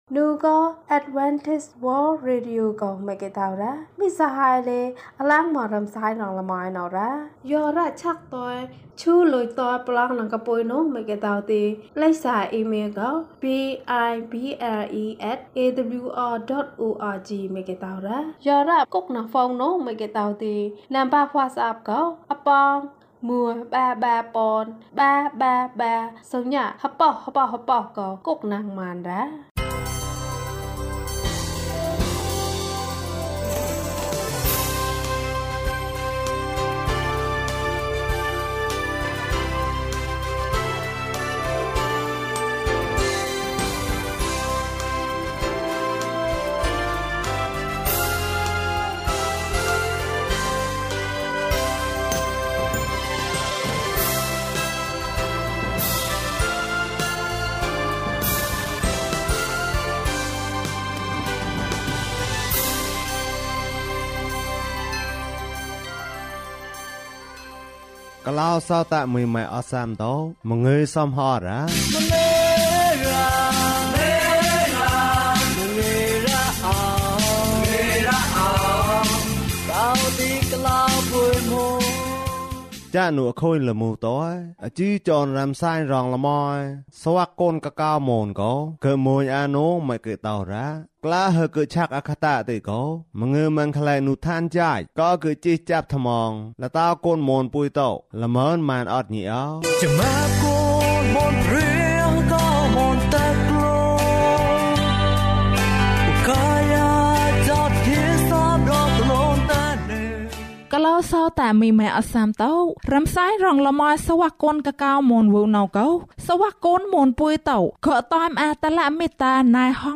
သင်၏ဆုတောင်းခြင်းကို ဇွဲမလျှော့နှင့်။ ကျန်းမာခြင်းအကြောင်းအရာ။ ဓမ္မသီချင်း။ တရားဒေသနာ။